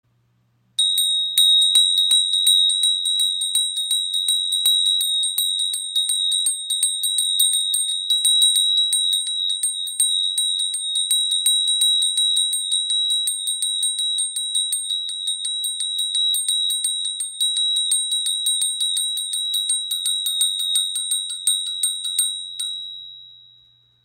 Ihr klarer Klang ist ideal für Meditation und Rituale.
Ein sanftes Schwingen, ein heller, vibrierender Ton – die Handglocke erklingt und erfüllt den Raum mit einer spürbaren Energie.
• Material: Messing